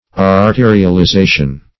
arterialization - definition of arterialization - synonyms, pronunciation, spelling from Free Dictionary
Search Result for " arterialization" : The Collaborative International Dictionary of English v.0.48: Arterialization \Ar*te`ri*al*i*za"tion\, n. (Physiol.)